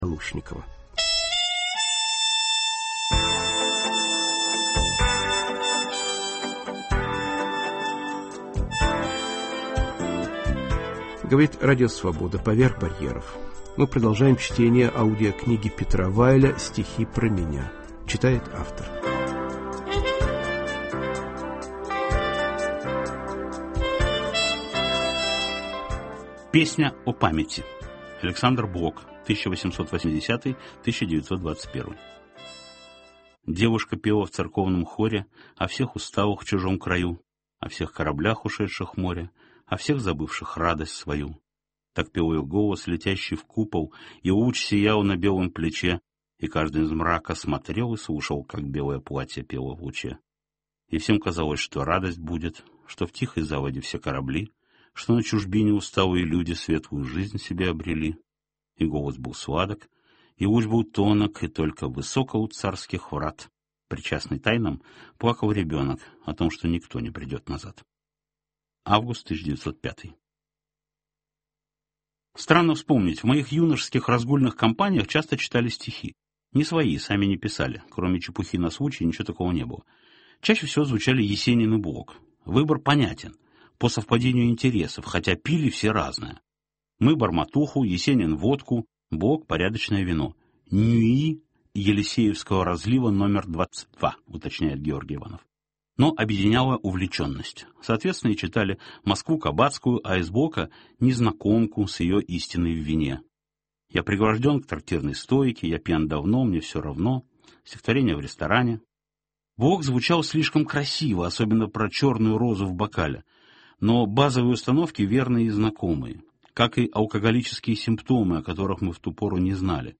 Аудиокнига Петра Вайля "Стихи про меня"